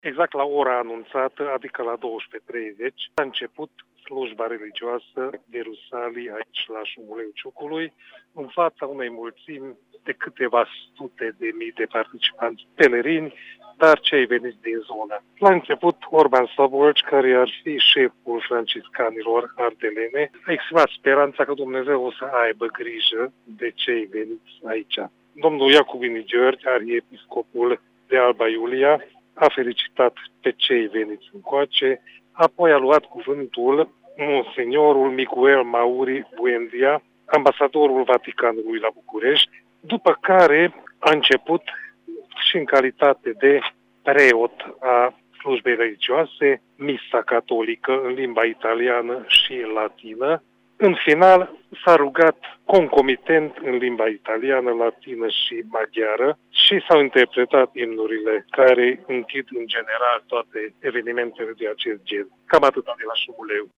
Aceasta a fost oficiată de Nunțiul Apostolic al Vaticanului la București în limbile latină și italiană. Cu mai multe detalii de la fața locului, colegul nostru